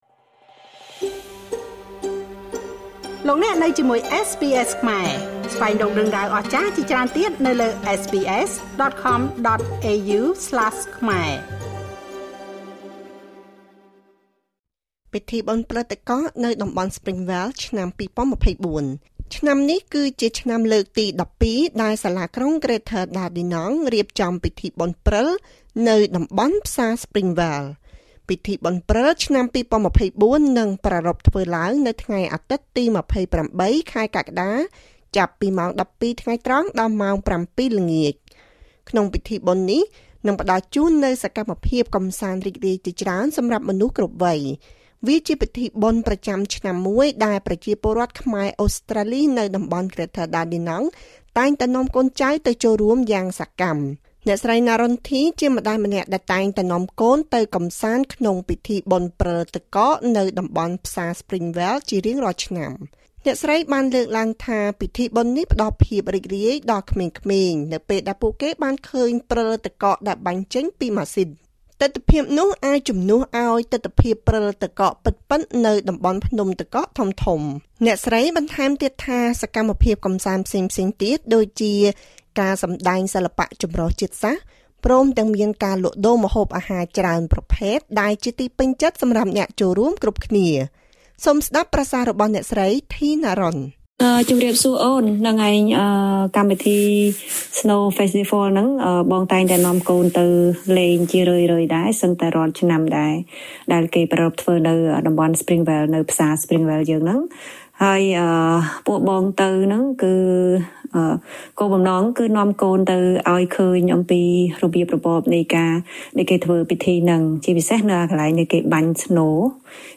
សូមស្តាប់ចំណាប់អារម្មណ៍របស់បងប្អូនខ្មែរអូស្រ្តាលី។